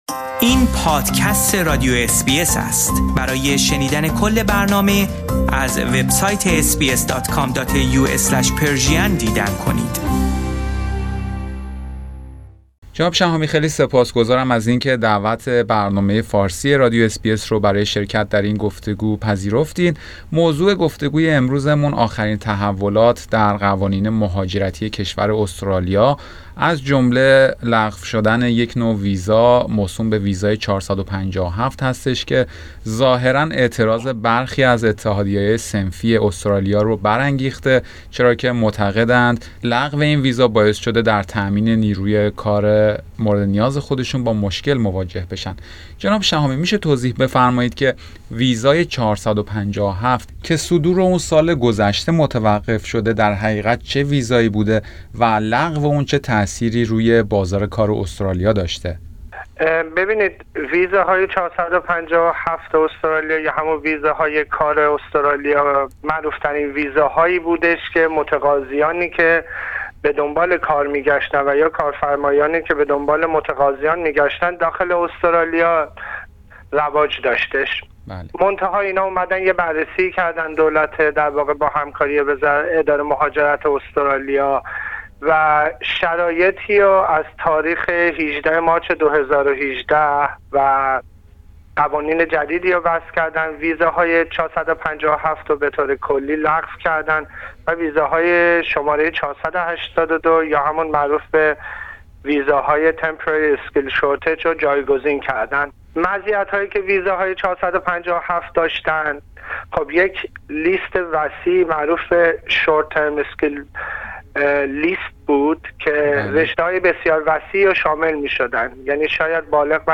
در گفتگو با برنامه فارسی رادیو SBS